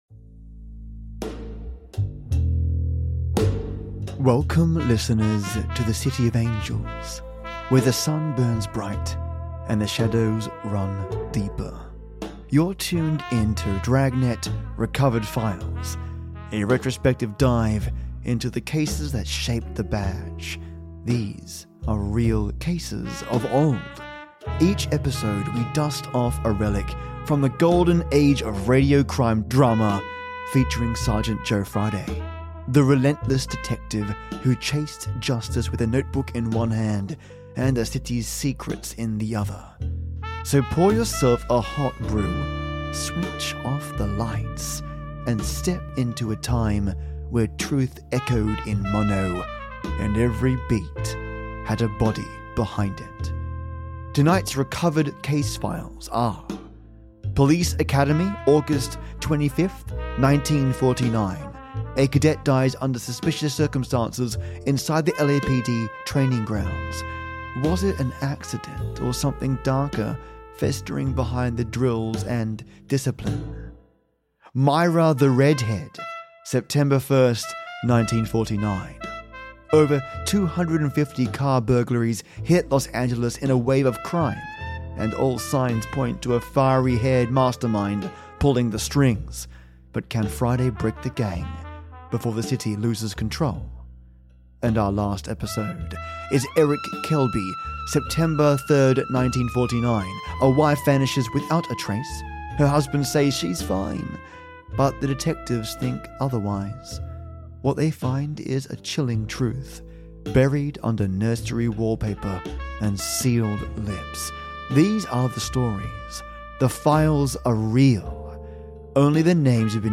Dragnet MEGA EPISODE - 3 Remastered Dragnet Ep's